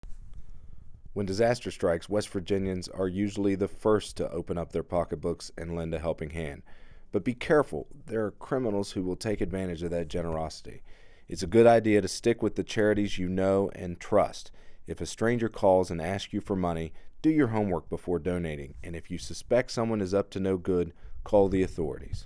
Click here to listen to an audio clip from U.S. Attorney Booth Goodwin